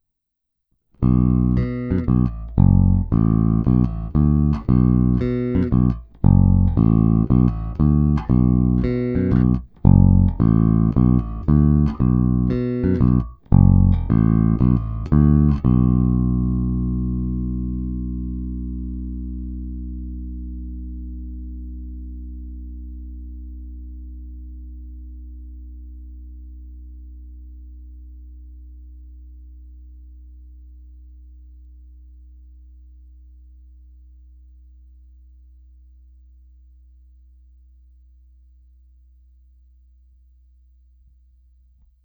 V každé poloze je zvuk hodně konkrétní, pevný, zvonivý.
Není-li uvedeno jinak, následující nahrávky jsou provedeny rovnou do zvukové karty, jen normalizovány, jinak ponechány bez úprav.
Hráno vždy mezi snímači, korekce ponechány ve střední poloze.